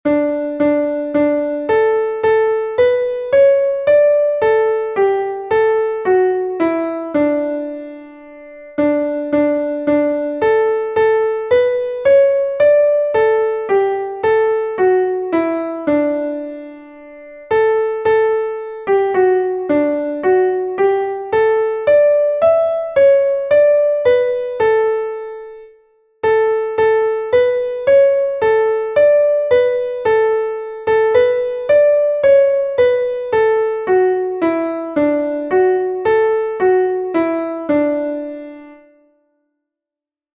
Einstimmige Melodie im Violinschlüssel, D-Dur, 4/4-Takt, mit der 1.
nach-gruener-farb-mein-herz-verlangt_klavier_melodiemeister.mp3